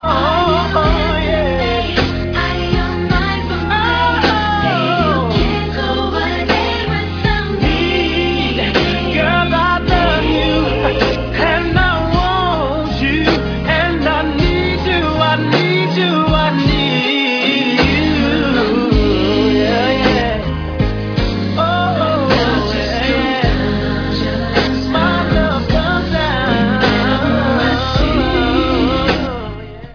background vocals, keyboards, and drum programming